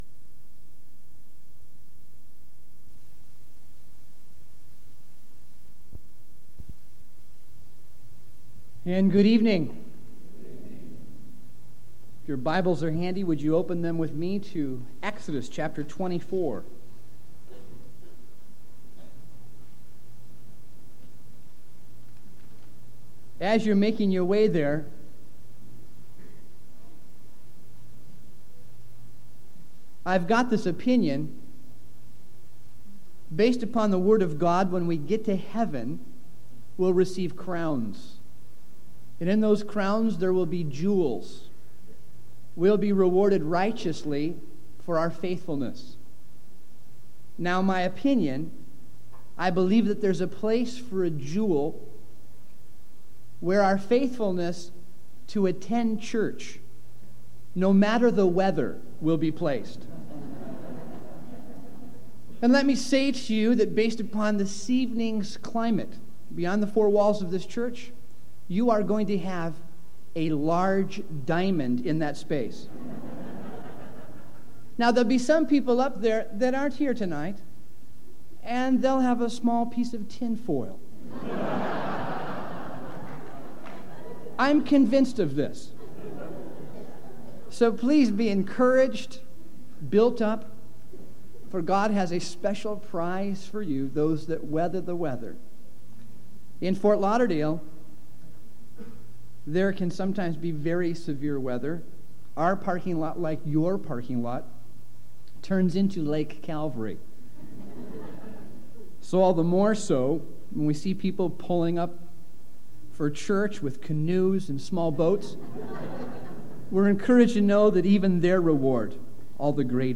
Home » Sermons » 1998 DSPC: Session 9